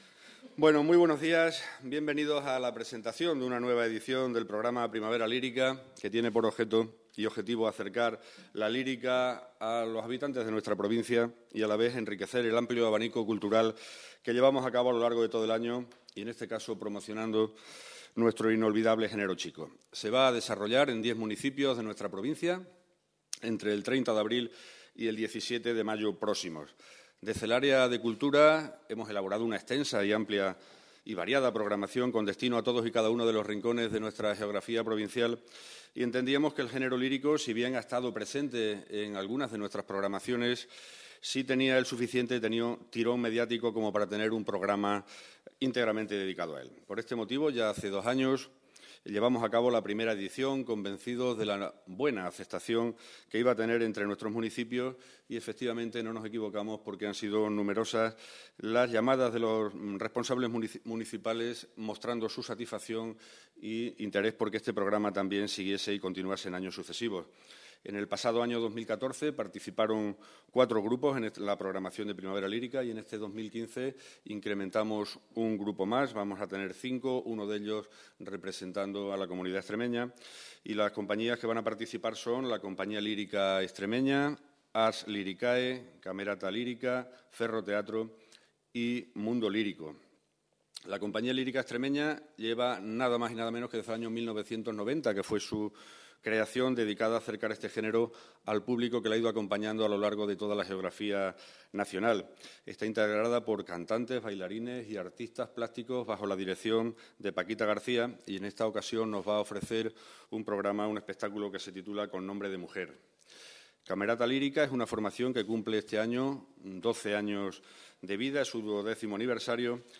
CORTES DE VOZ
21/04/2015-. El diputado de Cultura y Política Social, Eduardo Villaverde Torrecilla, ha presentado este martes en rueda de prensa la tercera edición del programa de conciertos ‘Primavera Lírica’ 2015, organizado por la Institución Cultural ‘El Brocense’ de la Diputación de Cáceres.